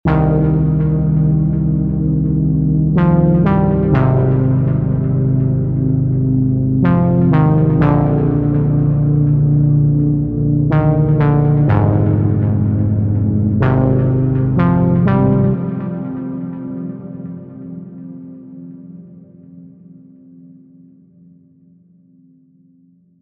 39 Basses
5 Plucks
Demo